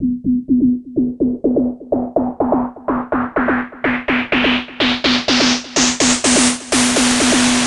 Index of /90_sSampleCDs/Classic_Chicago_House/FX Loops